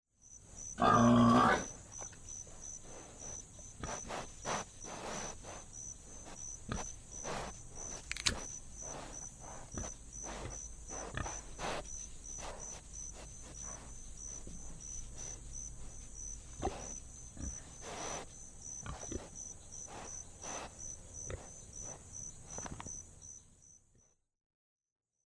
Звук бородавочника, вынюхивающего что-то